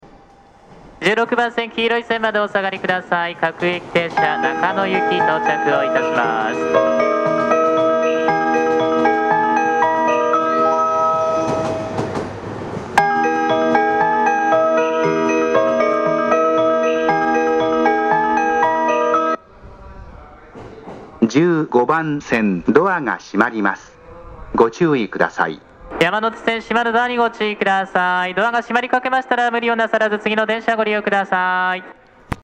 曲名は「twilight」です。こちらのホームも日中でも2コーラス目に入りやすいです。こちらの方が入りやすいですが、駅員放送も結構かぶり居やすいです。
新宿駅15番線